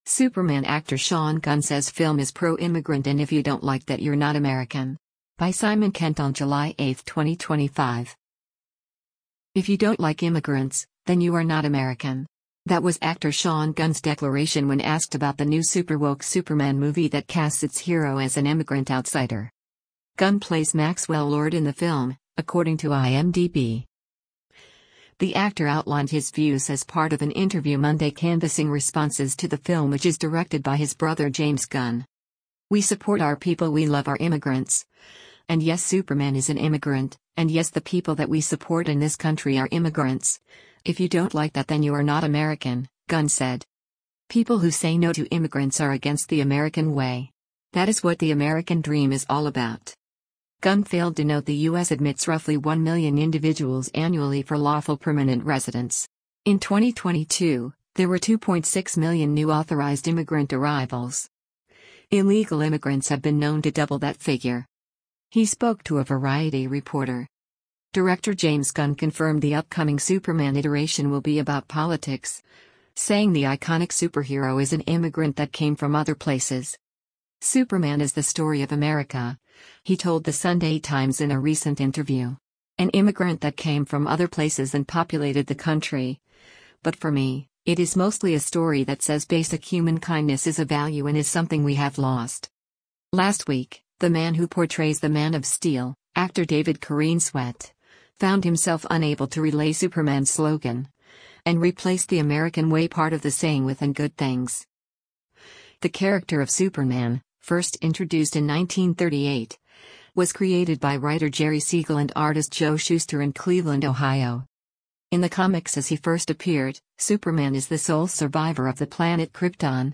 The actor outlined his views as part of an interview Monday canvassing responses to the film which is directed by his brother James Gunn.
He spoke to a Variety reporter: